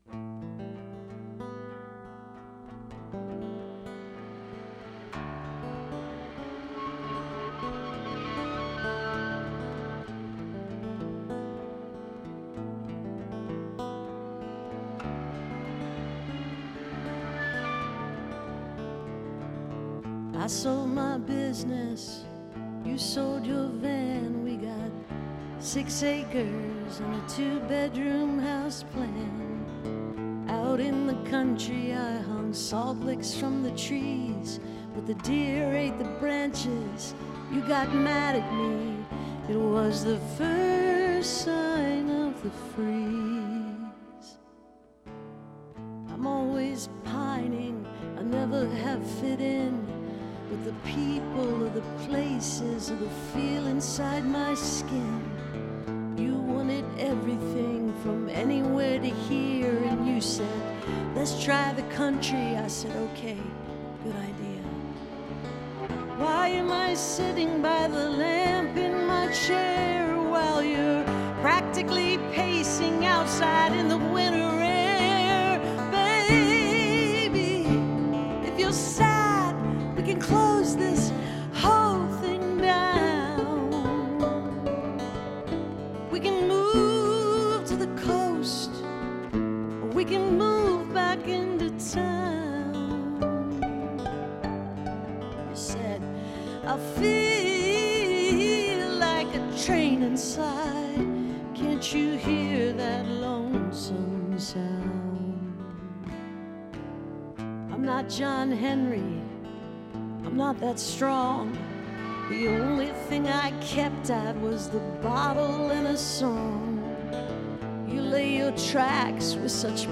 (captured from an online radio broadcast)